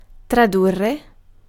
Ääntäminen
France (Paris): IPA: [tʁa.dɥiʁ]